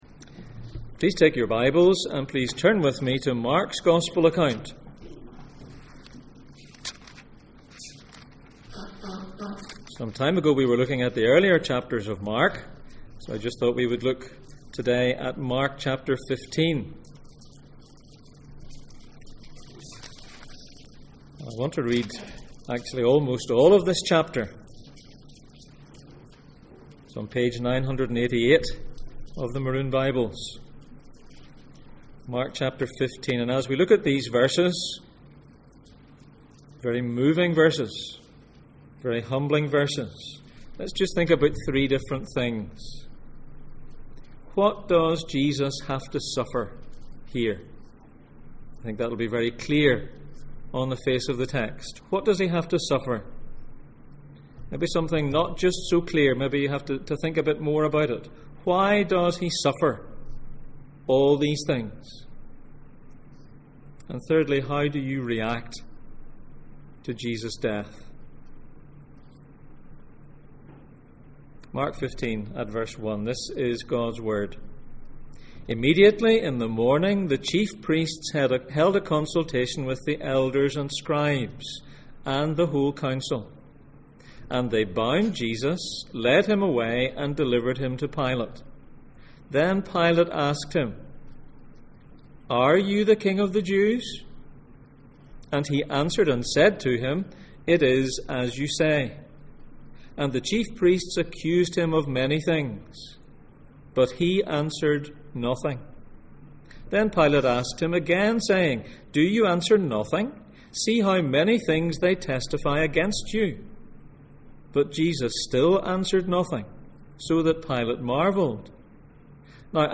Passage: Mark 15:1-41, Mark 10:32-34 Service Type: Sunday Morning